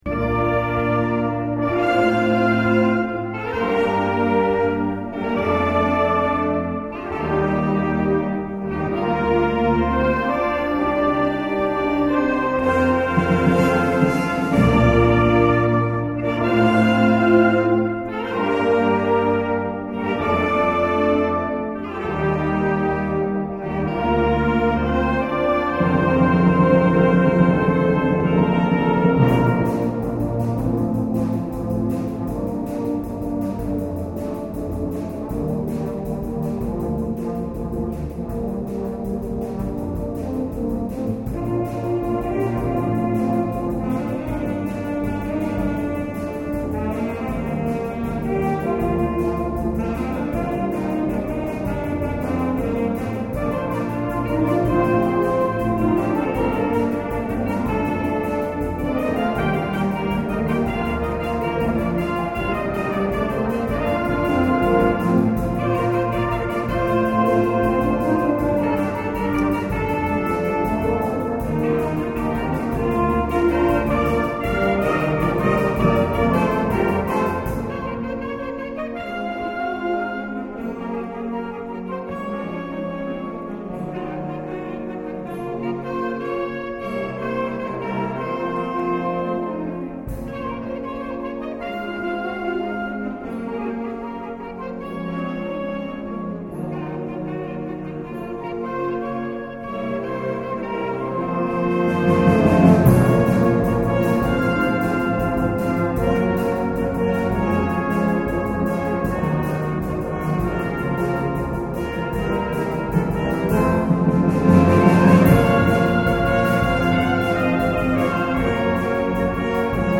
BANDA MUSICALE
Concerto di Natale 2010